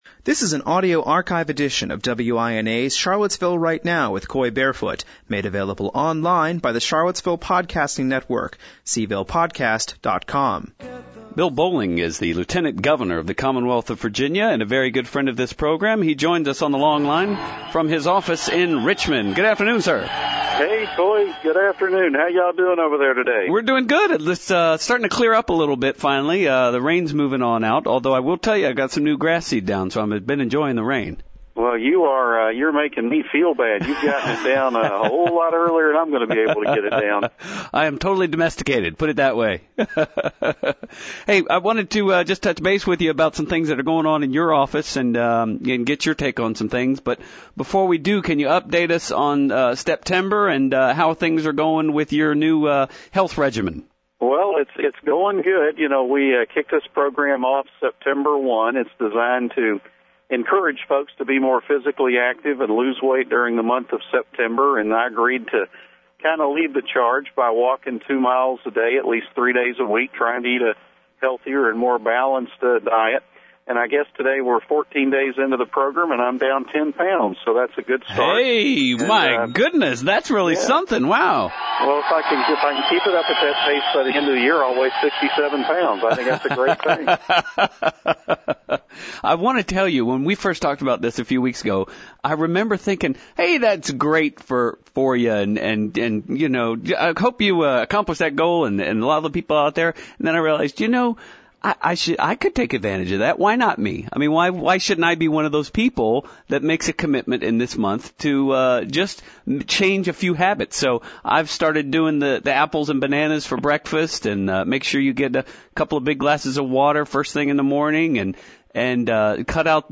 Charlottesville–Right Now: Lt. Governor Bolling previews health forum